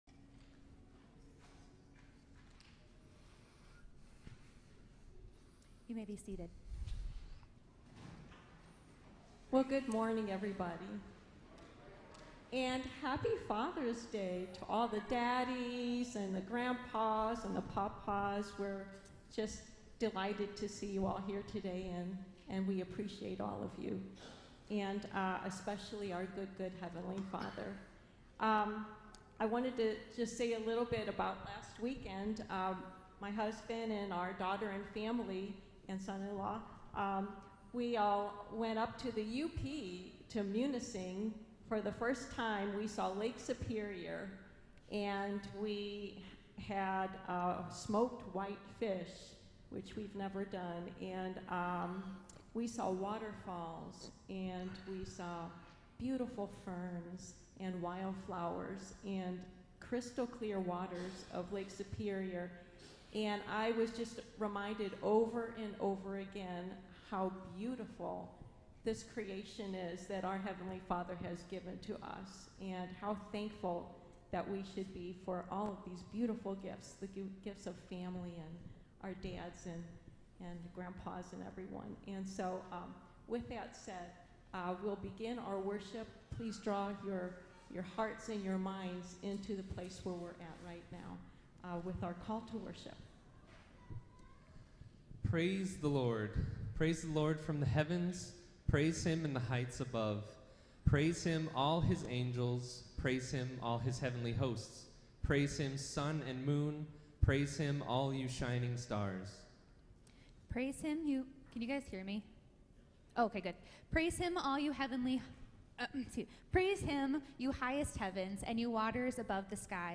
June 20, 2021 (Morning Worship)
Livestream-6-20-21.mp3